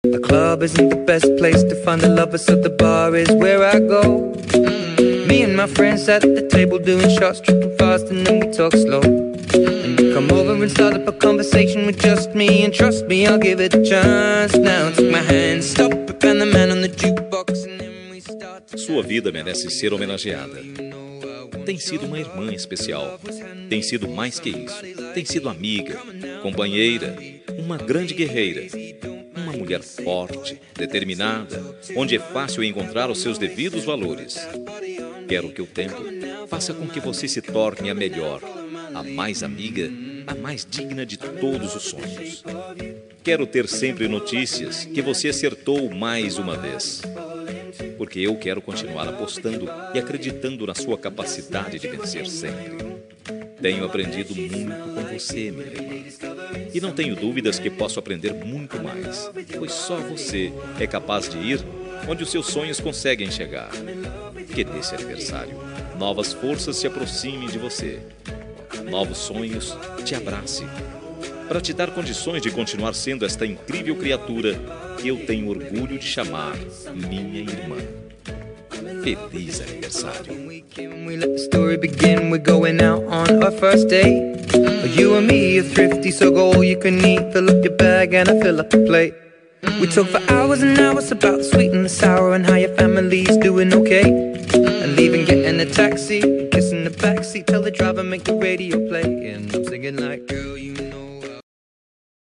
Telemensagem de Aniversário de Irmão – Voz Masculina – Cód: 4221 – Bonita